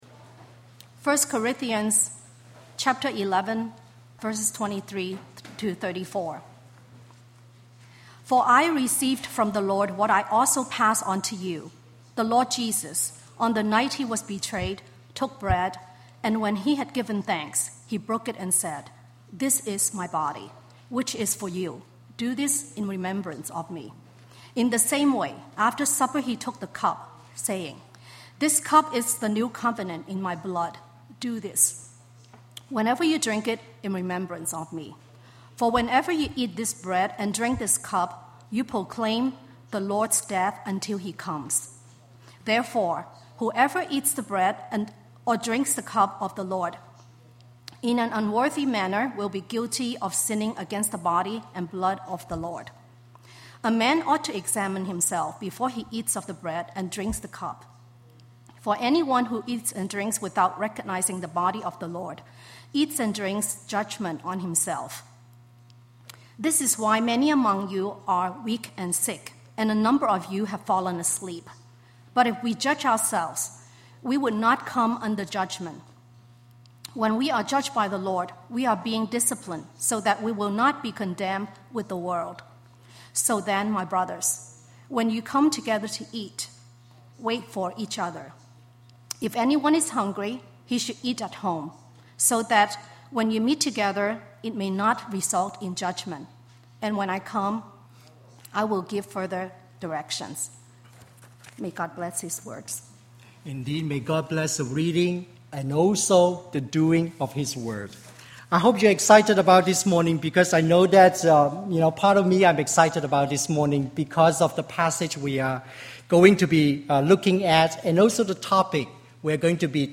Coming to the Table: August 2, 2009 Sermon